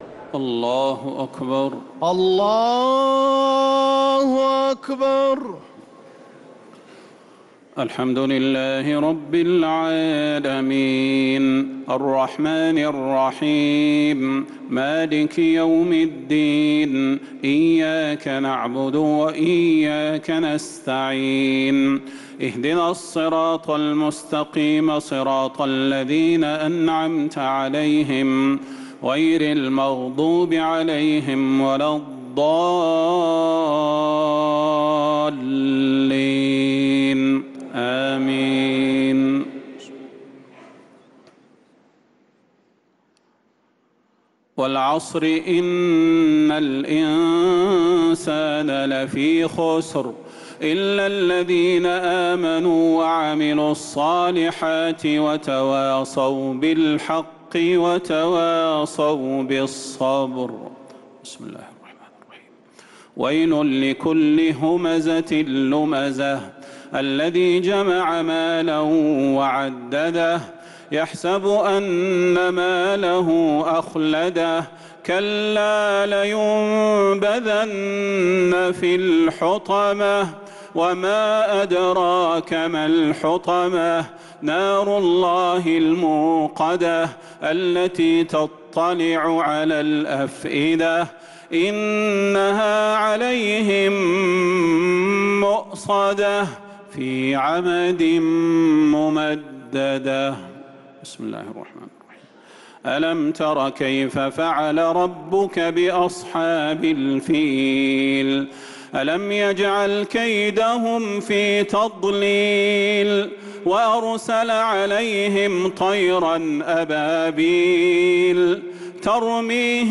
تراويح ليلة 29 رمضان 1446هـ من سورة العصر إلى سورة الناس | taraweeh 29th niqht Ramadan 1446H Surah Al-Asr to An-Naas > تراويح الحرم النبوي عام 1446 🕌 > التراويح - تلاوات الحرمين